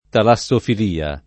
[ tala SS ofil & a ]